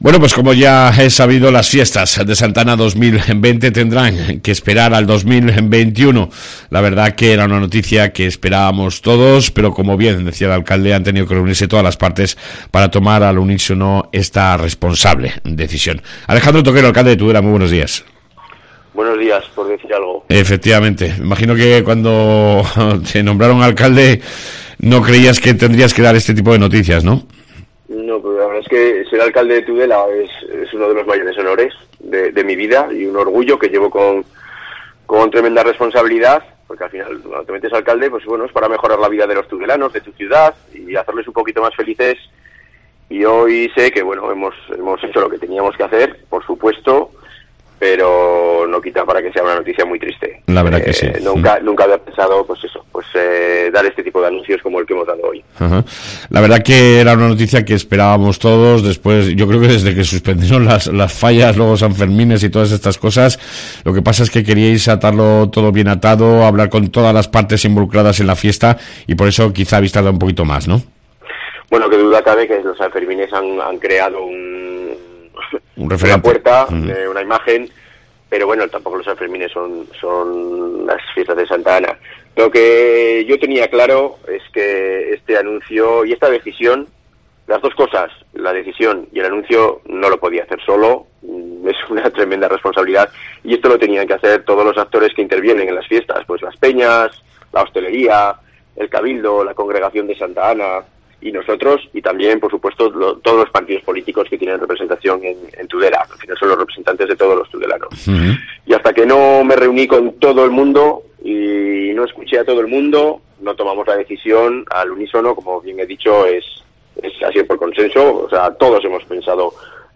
AUDIO: Entrevista con el Alcalde de Tudela Alejandro Toquero